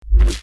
orb sound.mp3